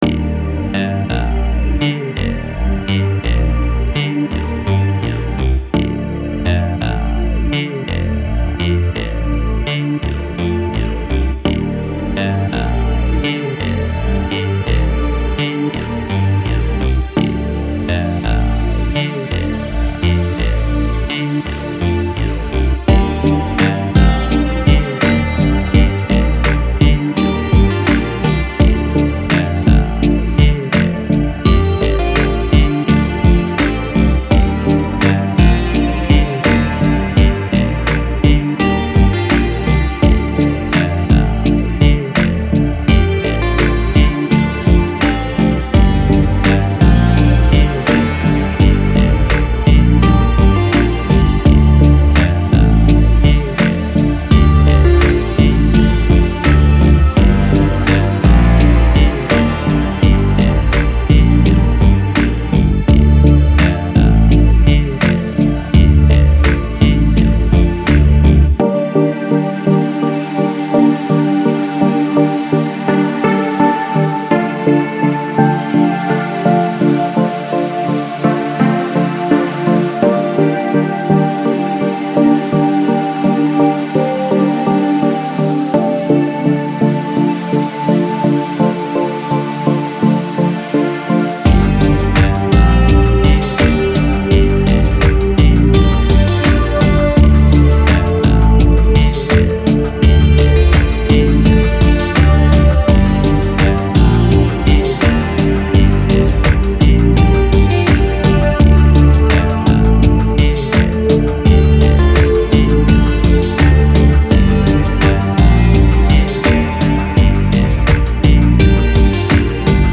FLAC Жанр: Lounge, Chill Out, Downtempo Год издания